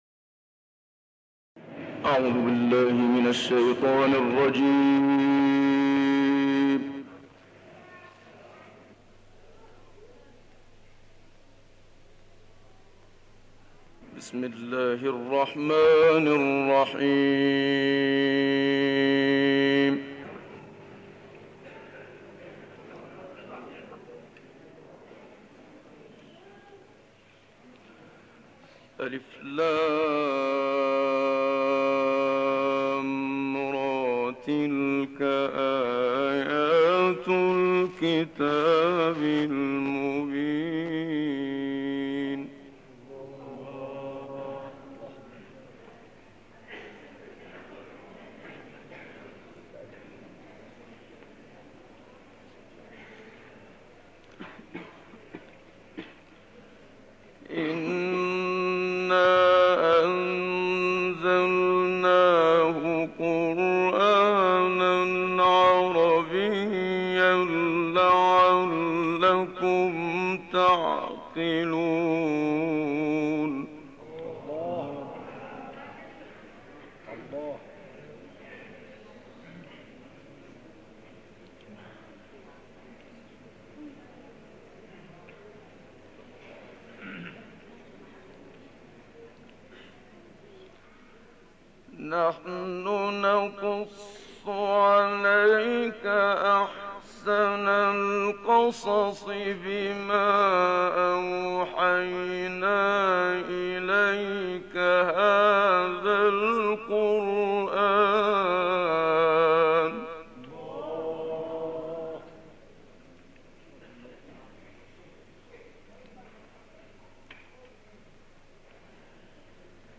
تلاوت سوره فجر با صدای استاد منشاوی + دانلود/ تهدید جباران به عذاب الهى